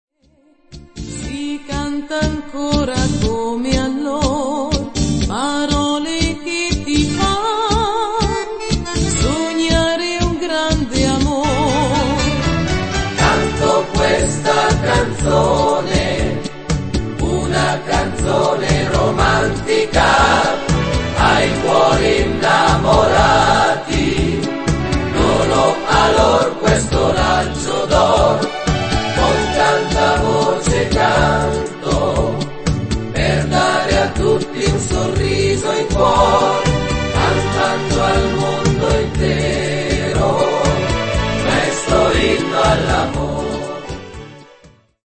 tango-beguine